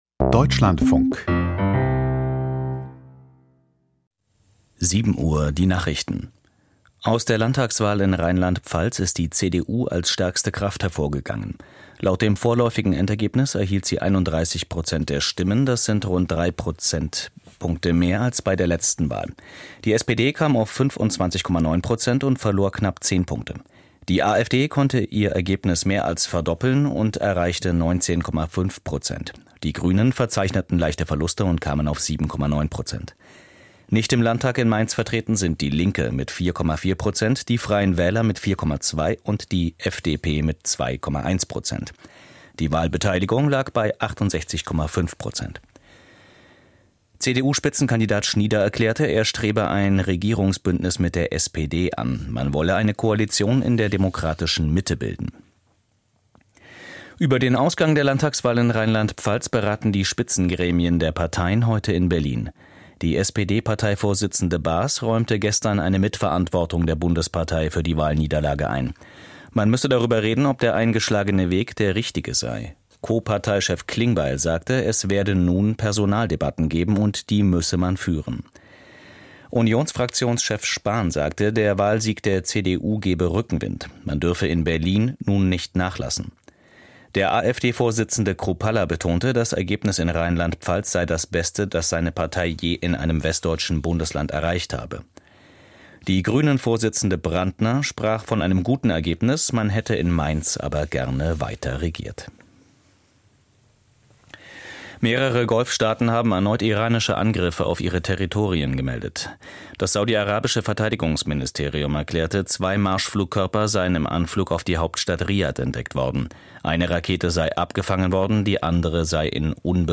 Die Nachrichten vom 23.03.2026, 07:00 Uhr
Aus der Deutschlandfunk-Nachrichtenredaktion.